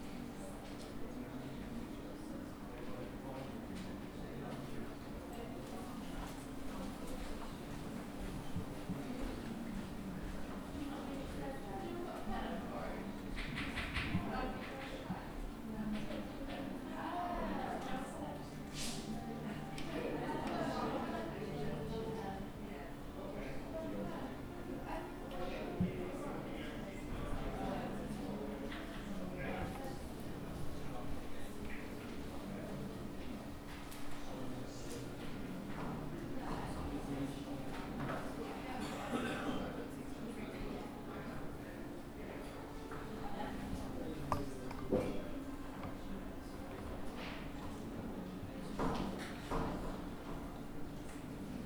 classroom_noise.wav